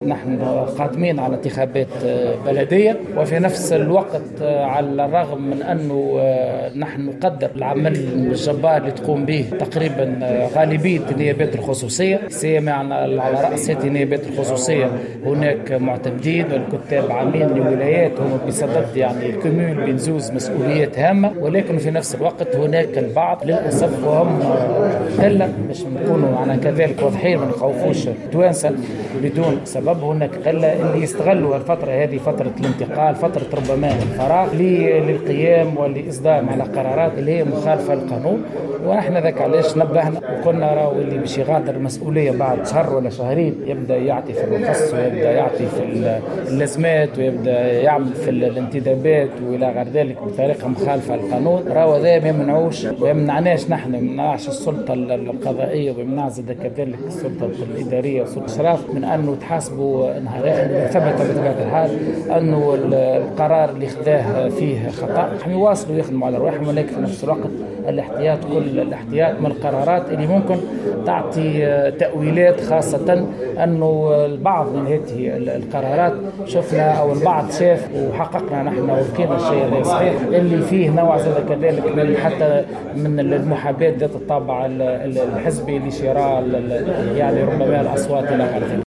على هامش اشرافه على يوم جهوي تحسيسي بقابس تحت شعار "قابس تكافح الفساد"